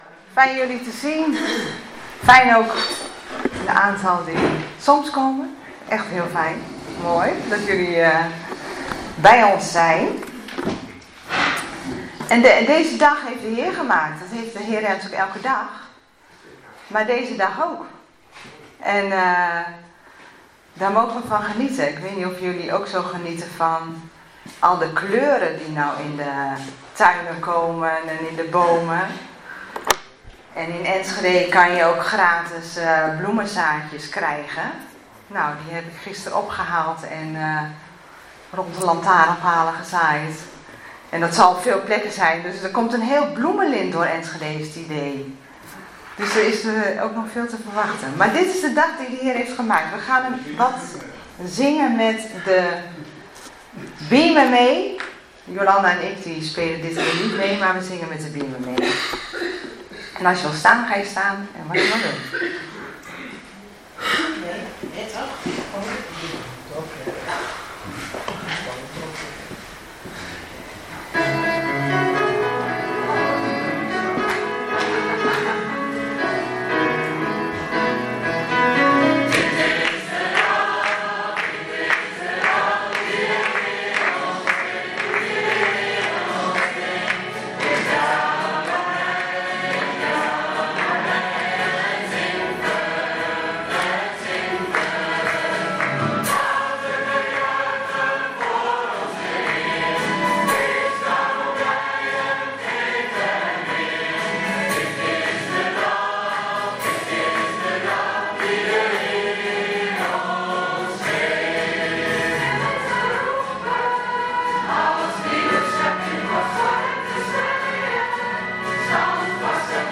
19 april 2026 dienst - Volle Evangelie Gemeente Enschede